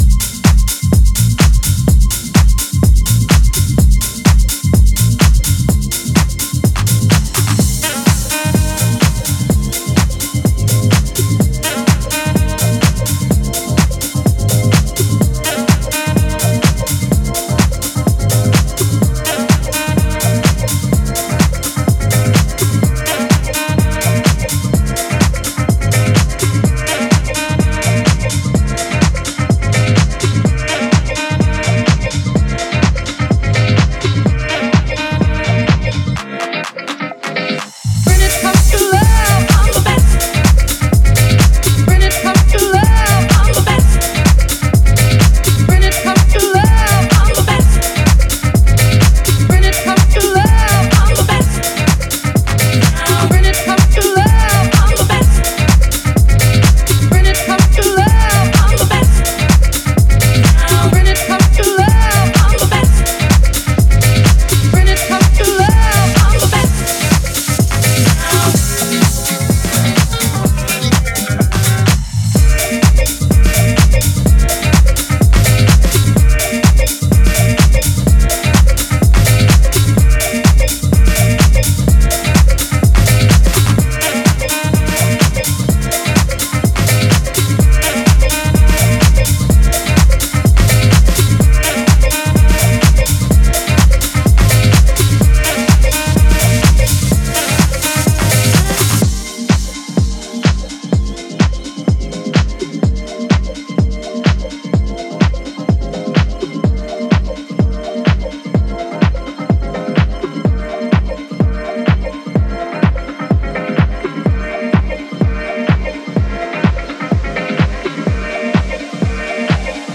定番的な楽曲からレアブギーまでをDJユースに捌いた